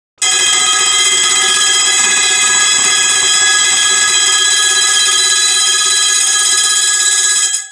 На этой странице собраны звуки трамваев: от ритмичного перестука колес по рельсам до характерных звонков и гула моторов.
Трамвай подает сигнал и отъезжает от остановки